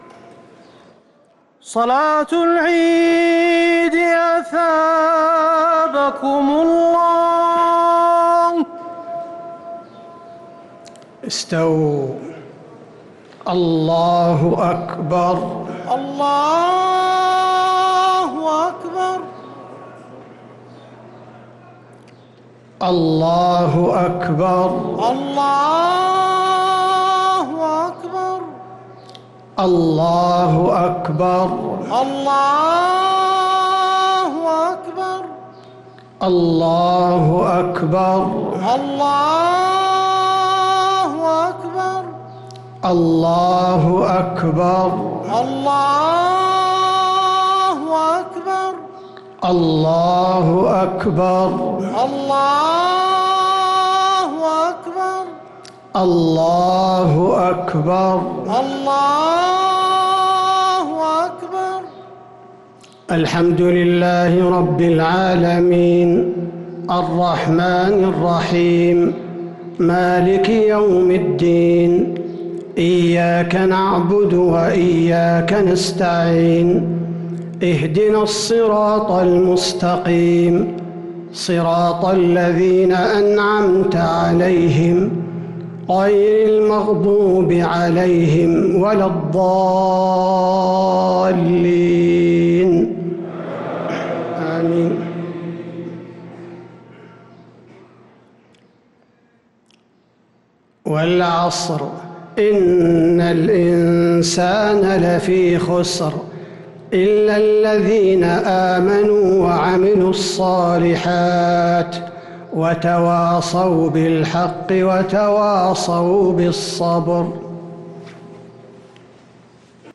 صلاة عيد الأضحى 1443هـ سورتي العصر و الإخلاص |Eid al-Adha prayer Surat al-`Asr and al-Ikhlas 9-7-2022 > 1443 🕌 > الفروض - تلاوات الحرمين